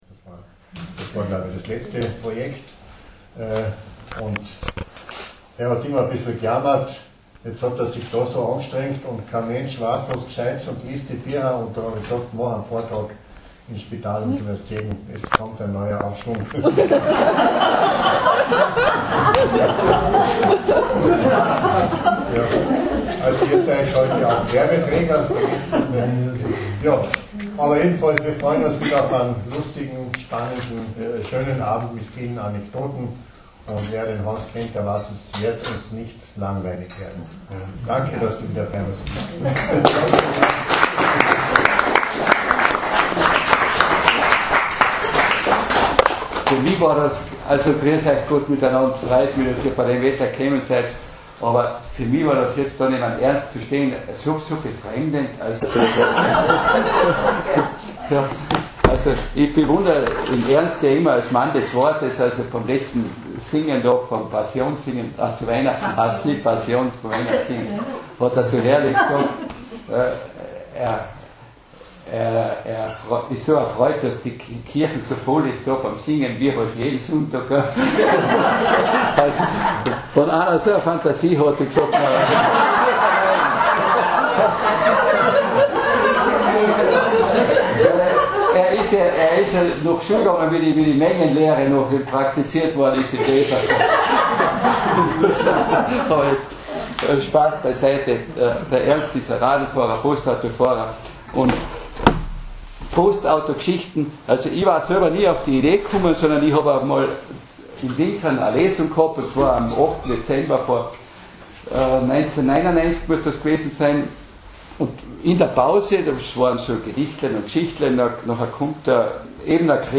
Abwechselnd mit Geschichten und spontanen Eigenkreationen mit Gitarrenbegleitung aus seiner unerschöpflichen gesanglichen Schatzkammer verstand er es, das Publikum zu fesseln und zu begeistern. Das Publikum bedankte sich seinerseits mit Beifallsstürmen.